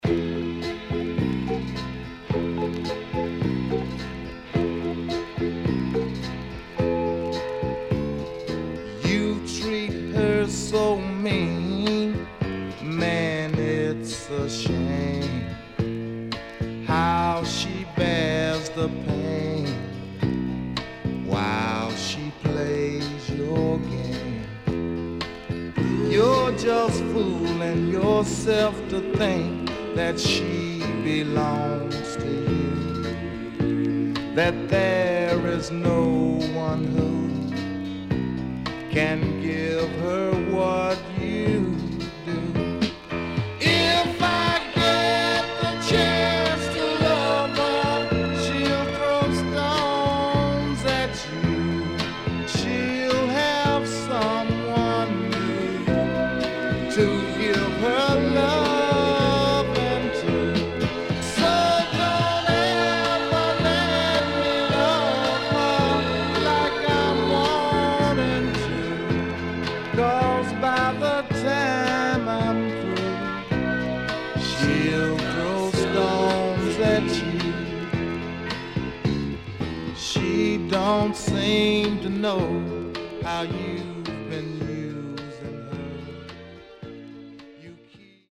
HOME > SOUL / OTHERS
CONDITION SIDE A:VG(OK)〜VG+
SIDE A所々チリノイズがあり、少しプチノイズ入ります。